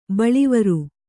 ♪ baḷivaru